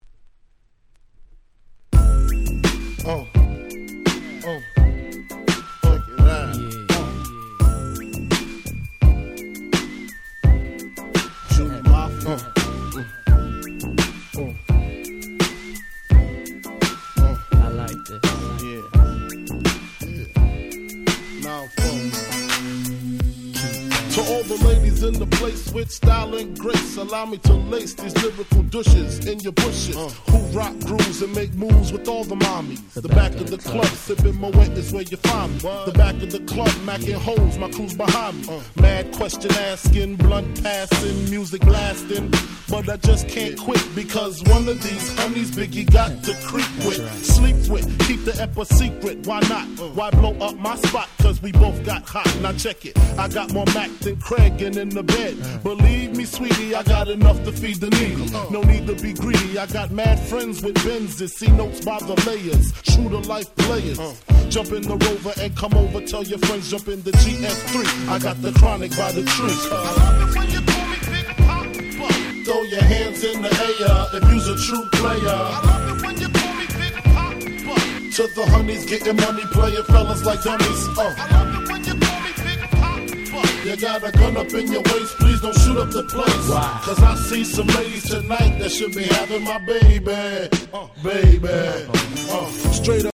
95' Super Hit Hip Hop !!
90's Boom Bap